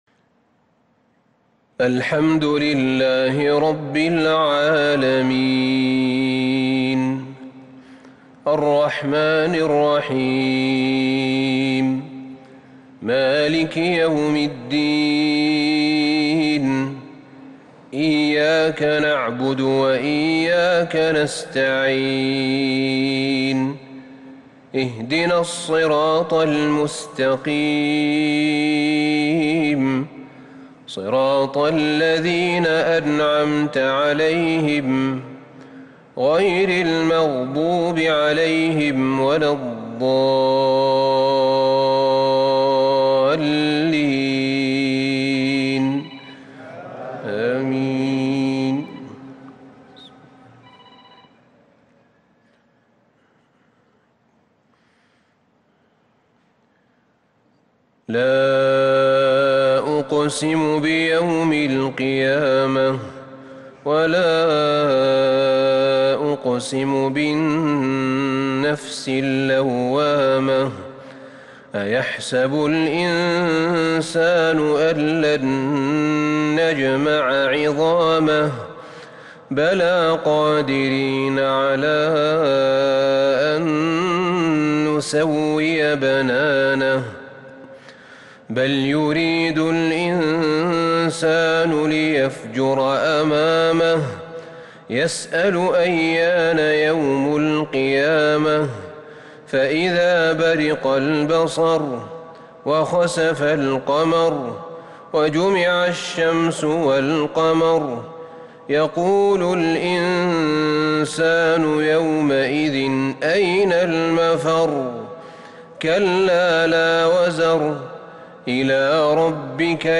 صلاة العشاء للشيخ أحمد بن طالب حميد 8 ذو الحجة 1442 هـ
تِلَاوَات الْحَرَمَيْن .